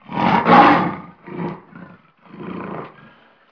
دانلود صدای حیوانات جنگلی 31 از ساعد نیوز با لینک مستقیم و کیفیت بالا
جلوه های صوتی